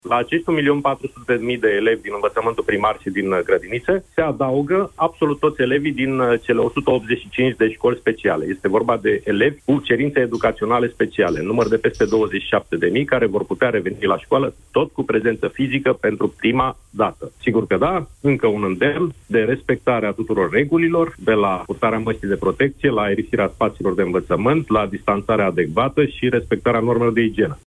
Ministrul Educației, Sorin Cîmpeanu, la Europa FM: